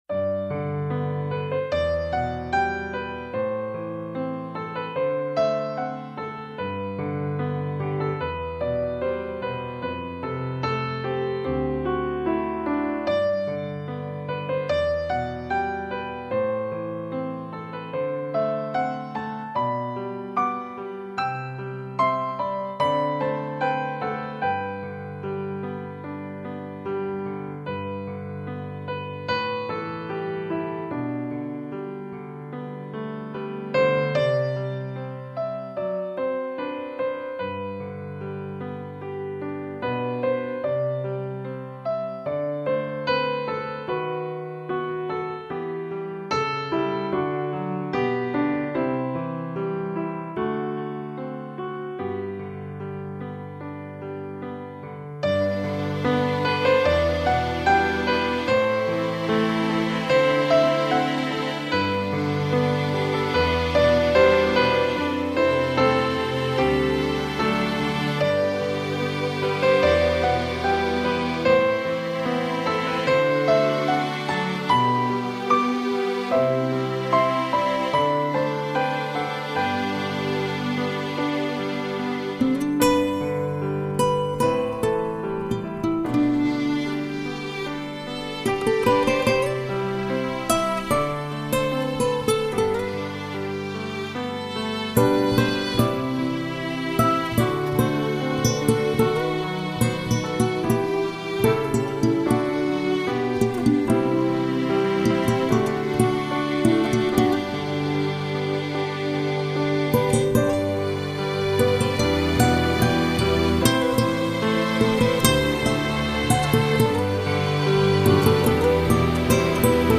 专辑语言：演奏专辑1CD
一种极简的生活音乐，来自网路和人生的真实交错